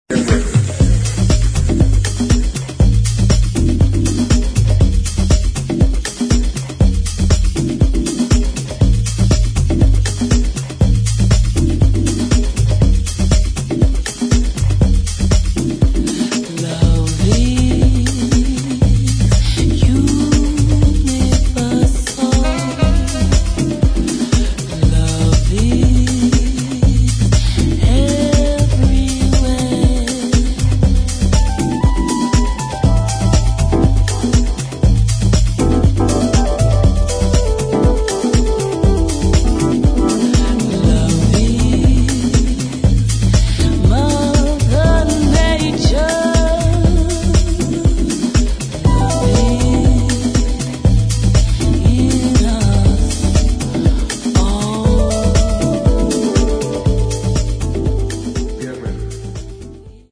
[ JAZZ | CROSSOVER ]
アフロ・テイストをテーマにしたクロスオーバー・シングル！！